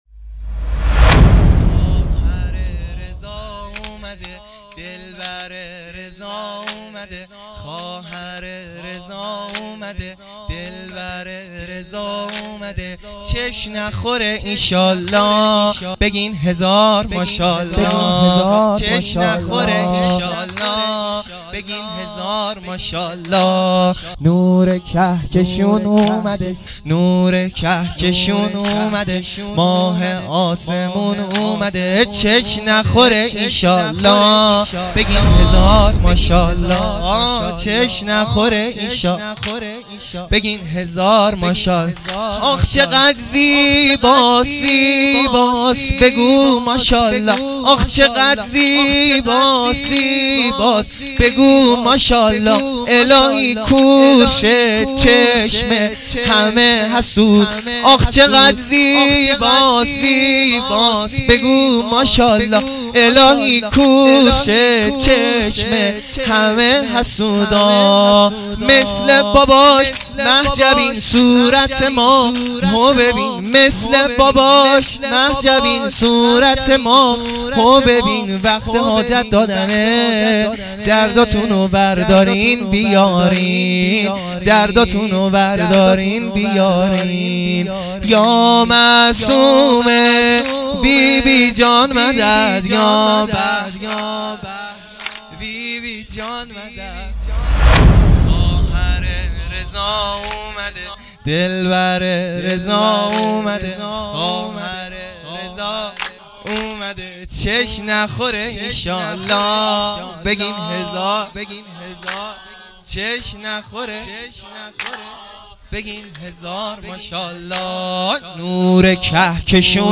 0 0 سرود
میلاد حضرت معصومه (س)